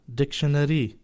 Both are pronounced quite similarly to their English counterparts. In these examples, though, the stress is on the second syllable in Pashto — the opposite to the stress used in English.